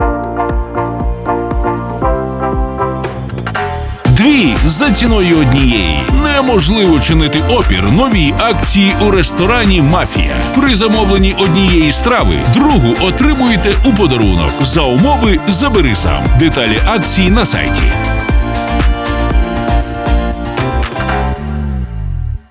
FireBrands – експерти зі звукового дизайну для радіо- і TV-реклами.